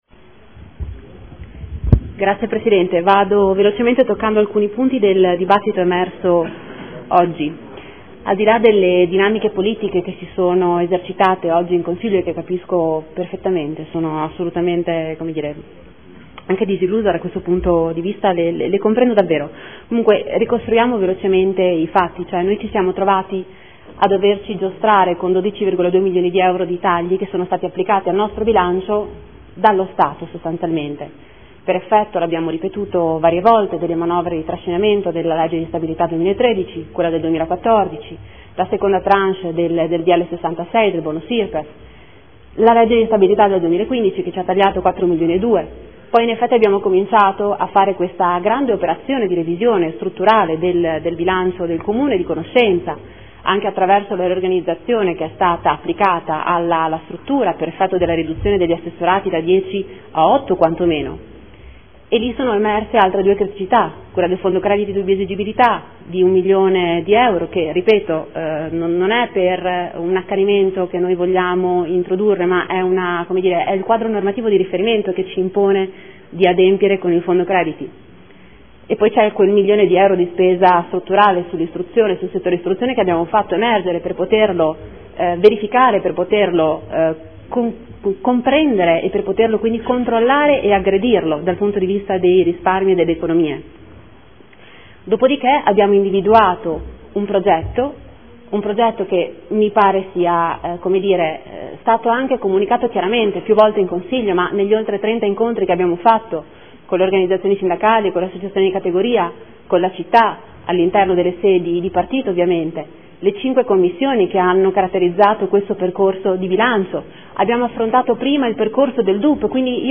Seduta del 05/03/2015 Dibattito sul Bilancio, sulle delibere, odg ed emendamenti collegati.